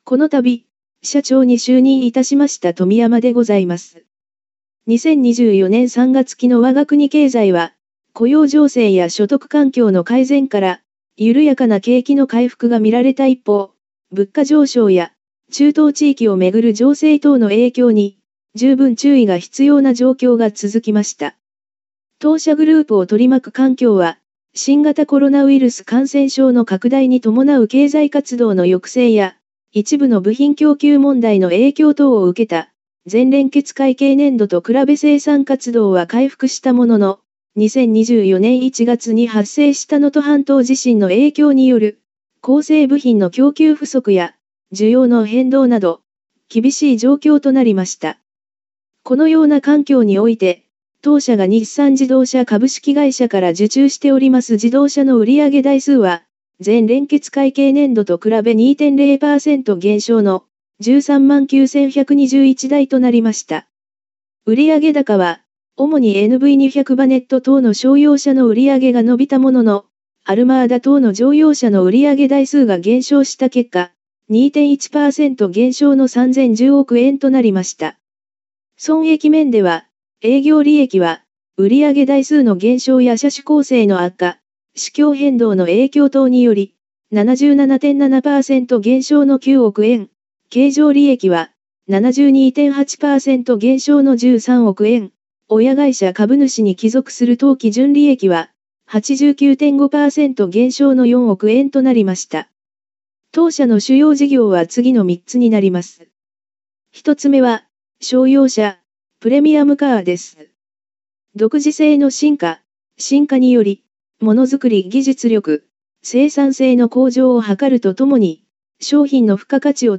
音声読み上げ